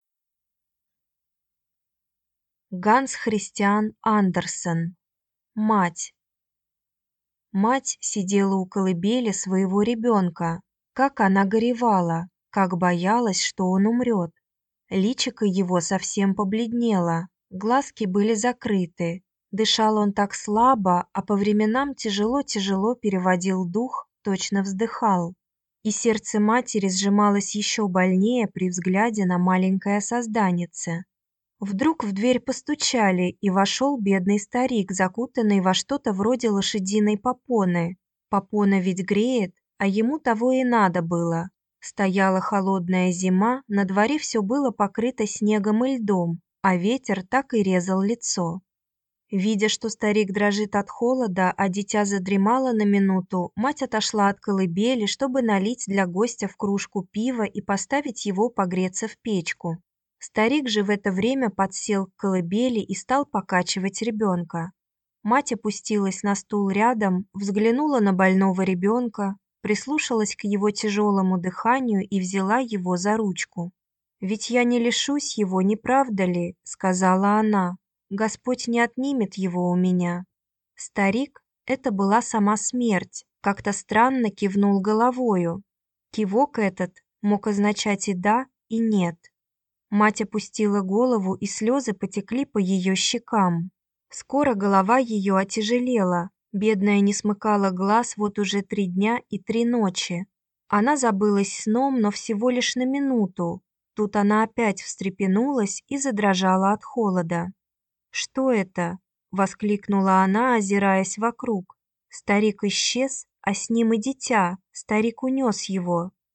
Аудиокнига Мать | Библиотека аудиокниг